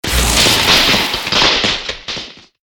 LB_sparks_1.ogg